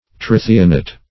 Trithionate \Tri*thi"on*ate\, n.